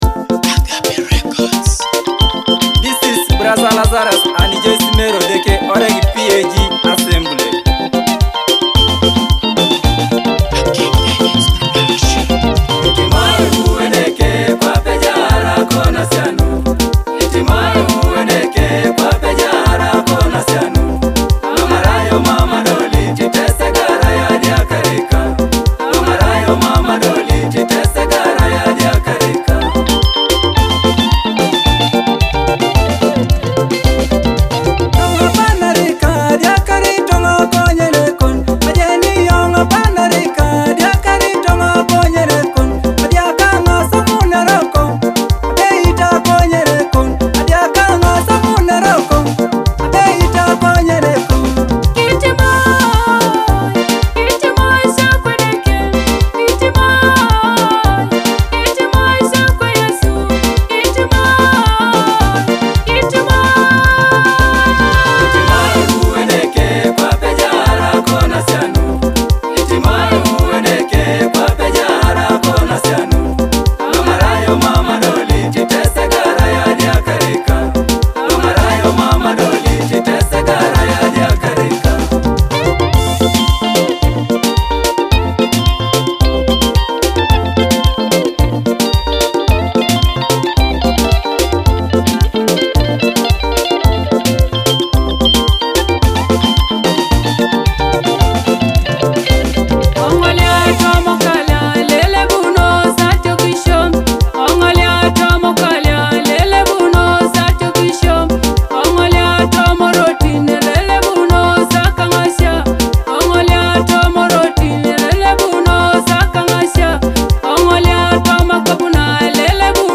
a powerful Teso gospel song meaning “Forgive Me Lord.”